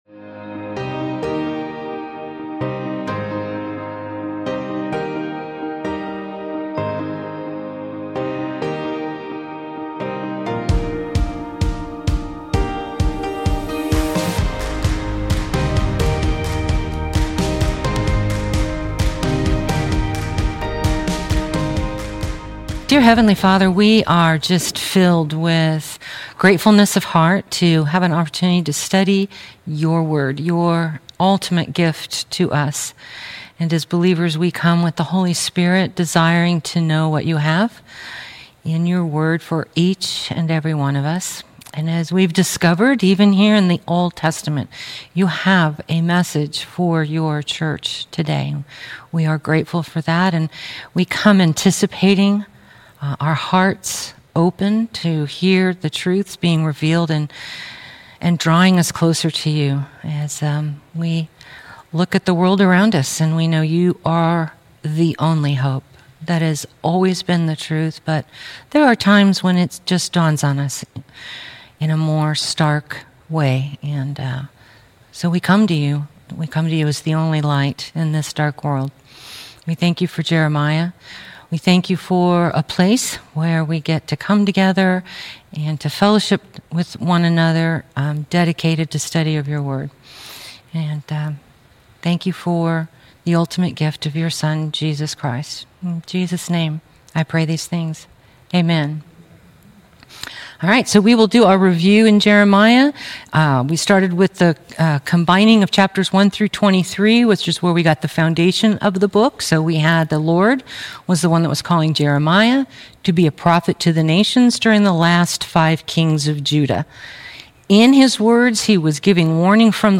Jeremiah - Lesson 35-36 | Verse By Verse Ministry International